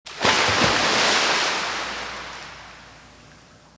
Jumping in Pool.m4a